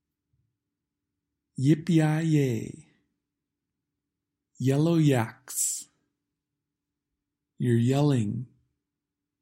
The /j/ sound